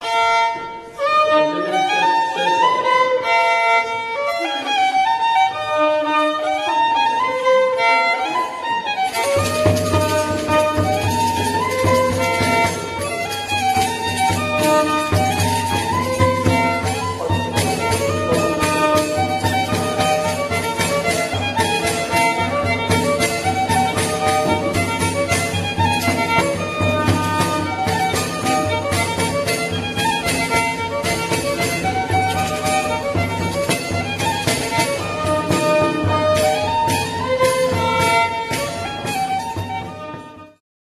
Polka Polka dance
The CD contains archival recordings made in 1993-2007 in the area of western Roztocze (Lubelskie region) and its surrounding villages.
skrzypce, ¶piew fiddle, vocals